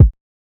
{Kick} lose.wav